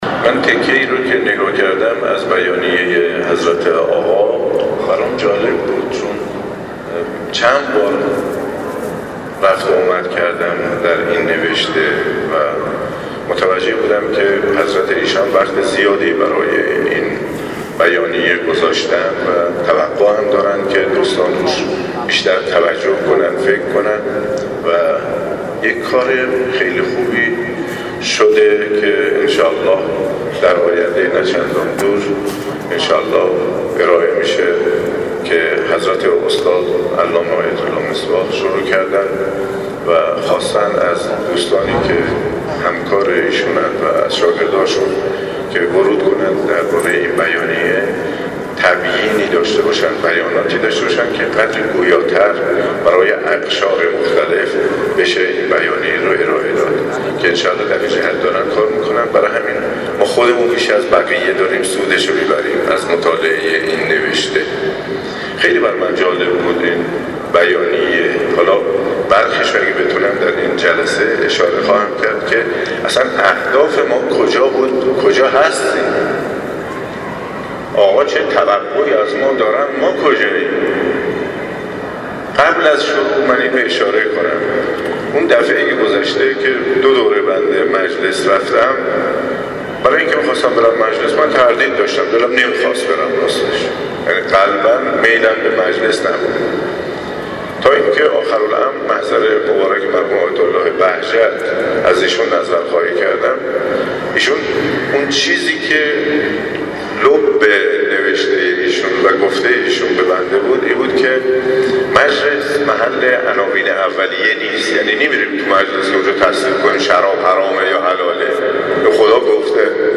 به گزارش خبرنگار سیاسی خبرگزاری رسا، حجت الاسلام والمسلمین مرتضی آقاتهرانی عضو هیأت علمی مؤسسه آموزشی و پژوهشی امام خمینی(ره) صبح امروز در بیست و پنجمین همایش سراسری تبیین بیانیه گام دوم انقلاب و الزامات آن که در مصلی قدس قم برگزار شد، با اشاره به اینکه رهبر معظم انقلاب برای نگاشتن بیانیه گام دوم وقت بسیاری گذاشته اند، گفت: ما باید برای تبیین این بیانیه ارزشمند وقت بگذاریم و راحت از کنار آن عبور نکنیم.